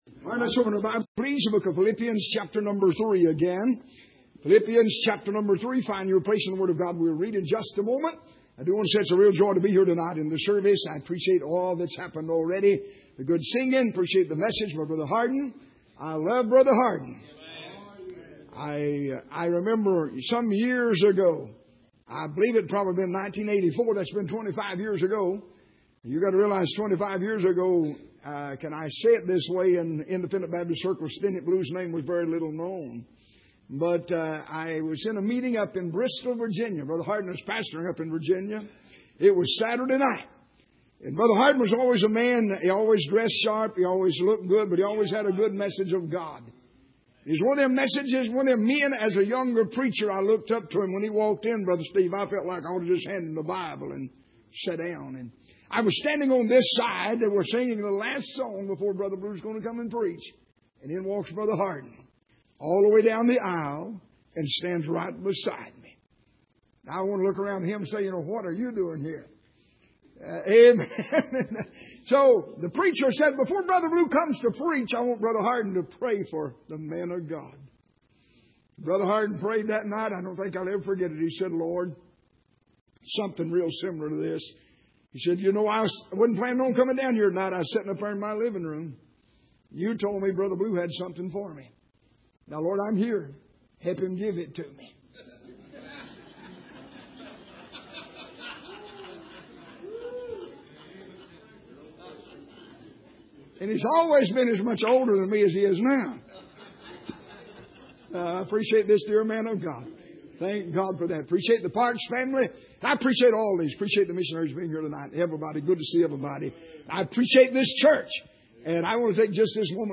Passage: Philippians 3:7-14 Service: Special Service